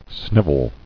[sniv·el]